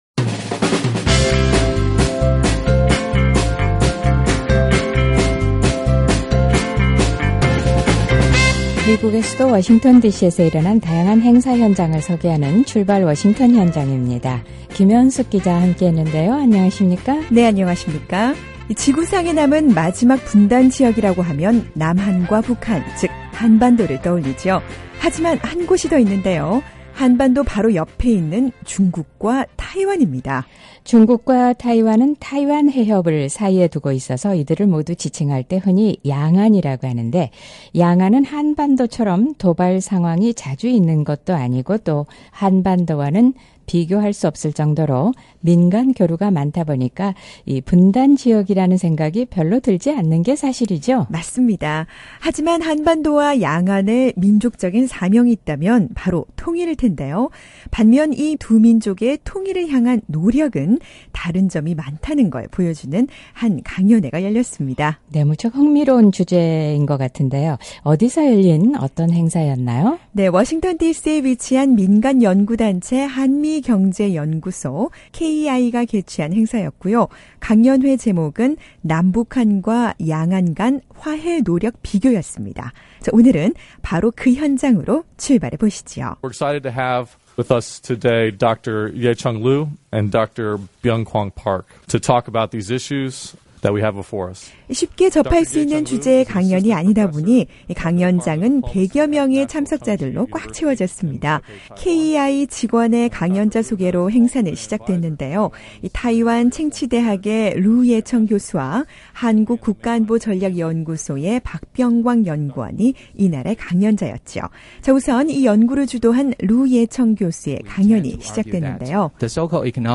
과연 이 두 지역은 통일을 위해 어떤 노력을 하고 있을까요? 한반도와 양안은 통일이라는 공통된 사명을 안고 있지만 이 두 민족의 통일을 향한 노력은 많이 다르다는 것을 보여주는 이색적인 강연회가 열렸는데요, 오늘은 그 현장으로 출발해봅니다.